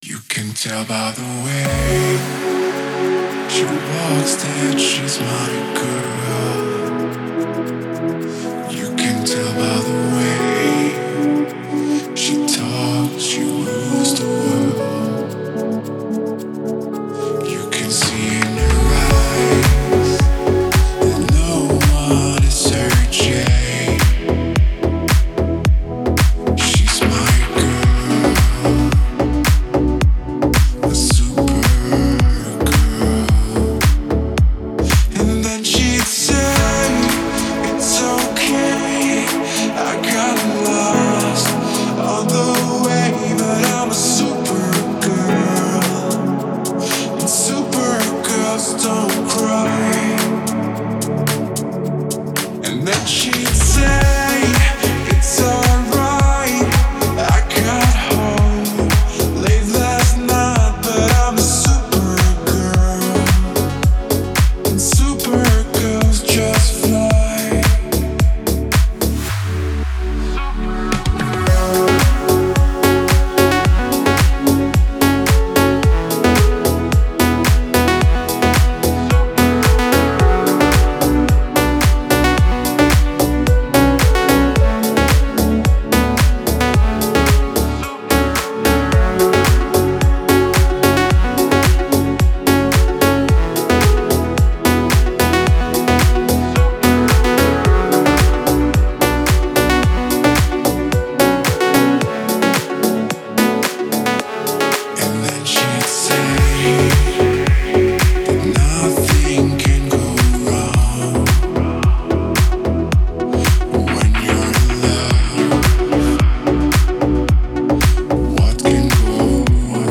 Красивые ремейки 2025